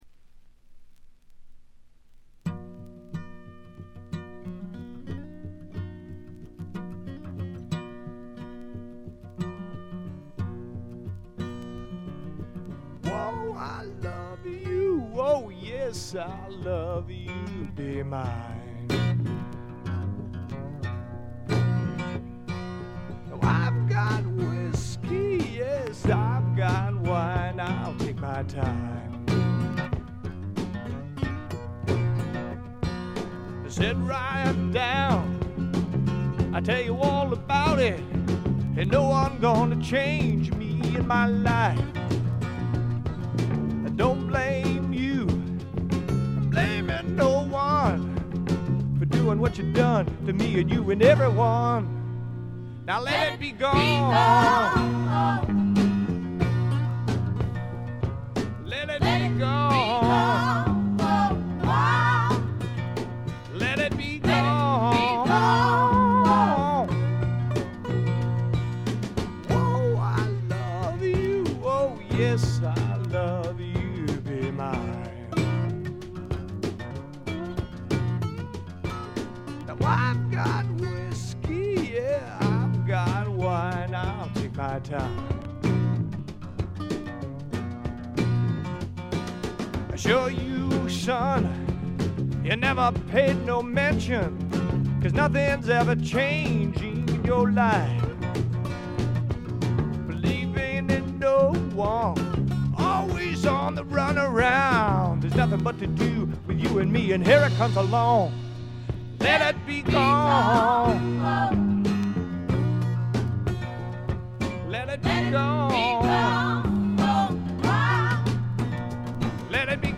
静音部で軽微なバックグラウンドノイズが少し。
乾ききった硬質感で統一された見事なスワンプロック！
試聴曲は現品からの取り込み音源です。